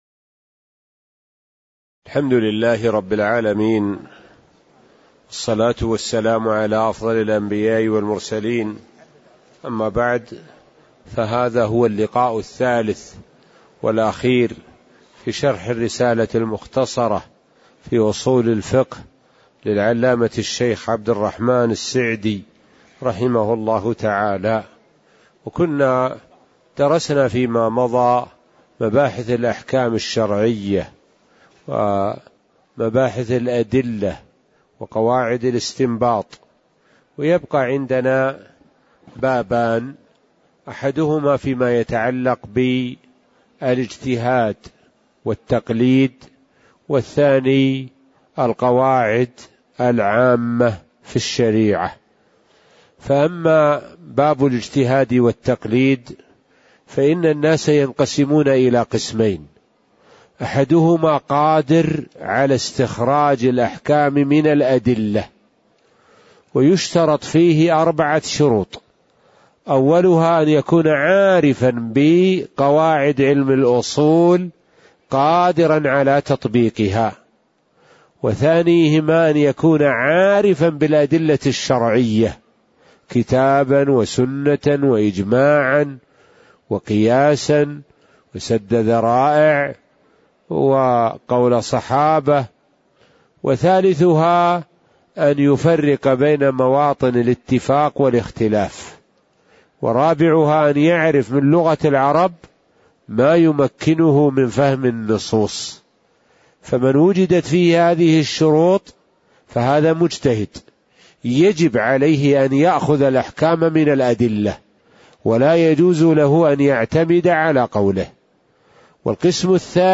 تاريخ النشر ٢٥ شوال ١٤٣٧ هـ المكان: المسجد النبوي الشيخ: معالي الشيخ د. سعد بن ناصر الشثري معالي الشيخ د. سعد بن ناصر الشثري الإجتهاد والتقليد (03)